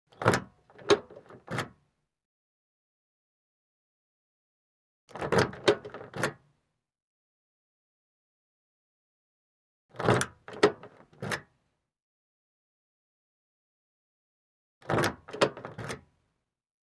Звуки двери автомобиля